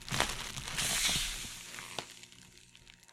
气体泄漏/轮胎放气
描述：气体泄漏/轮胎放气
标签： 空气 压缩 泄漏 放气 气体
声道立体声